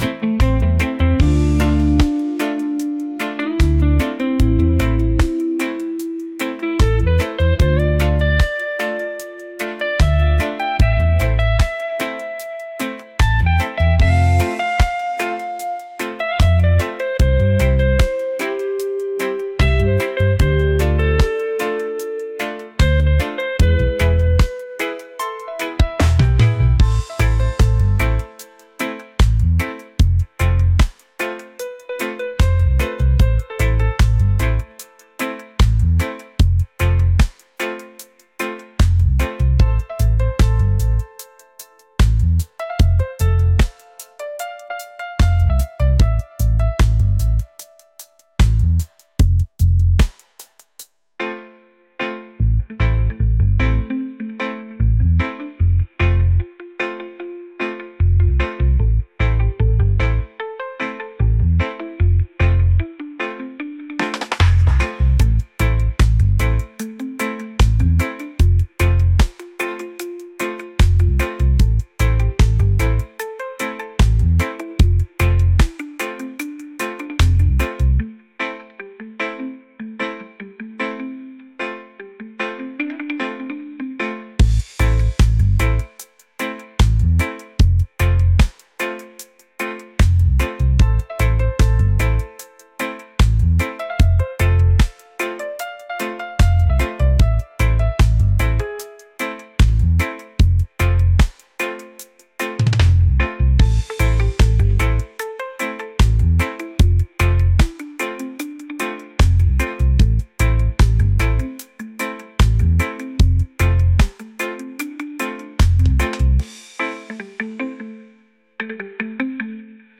groovy | reggae